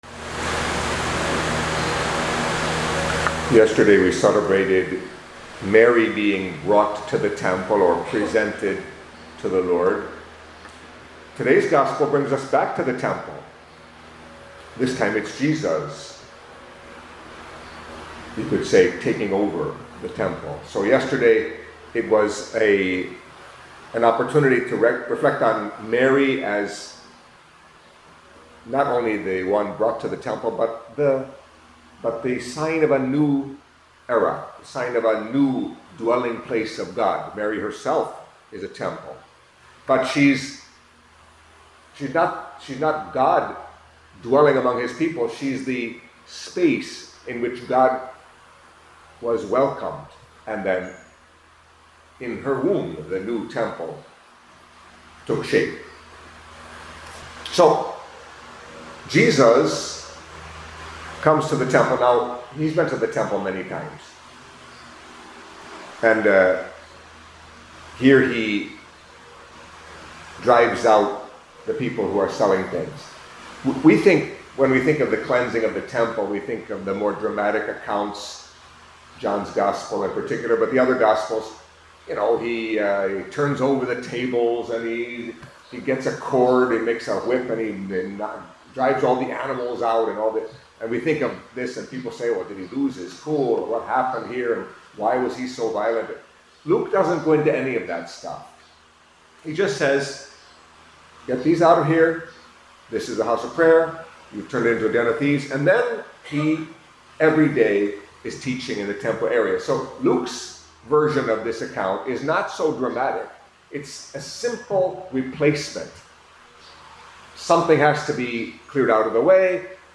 Catholic Mass homily for Friday of the Thirty-Third Week in Ordinary Time